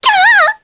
Laias-Vox_Damage_jp_03.wav